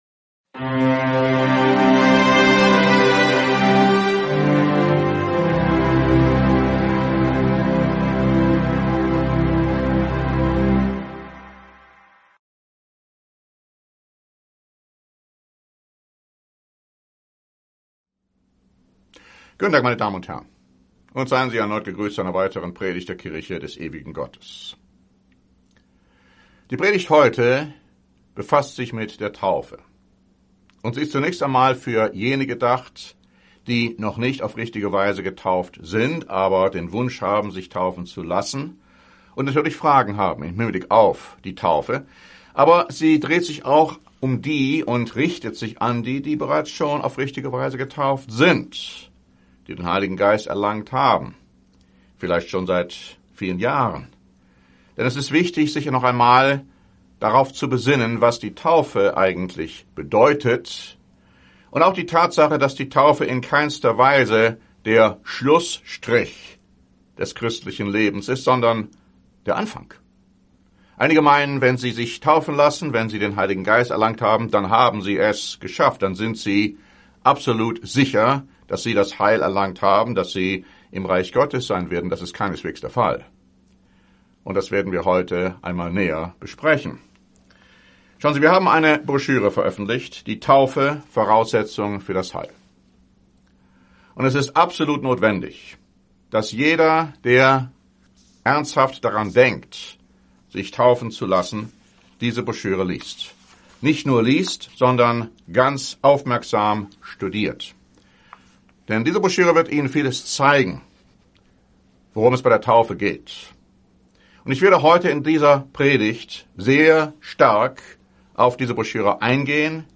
In dieser Predigt wird anhand der Bibel erklärt, wann sich jemand taufen lassen sollte–und wann (noch) nicht.